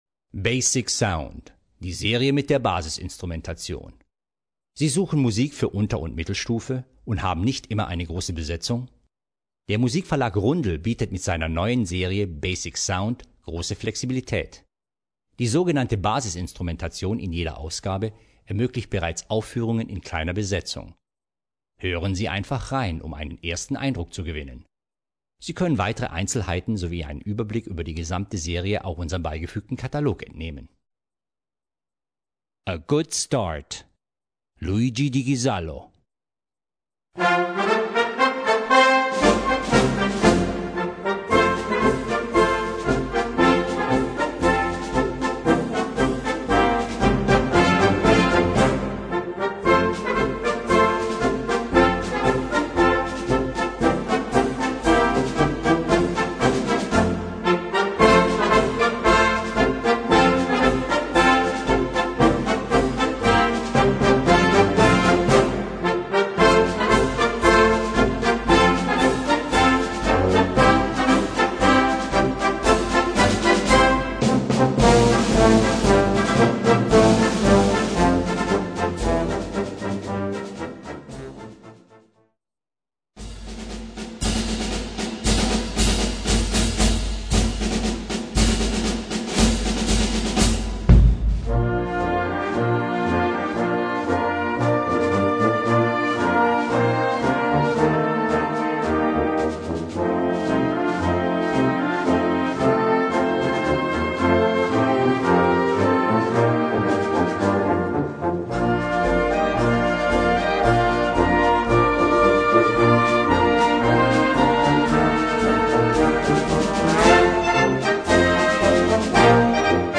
Gattung: Marsch
Besetzung: Blasorchester
ist ein heiterer Marsch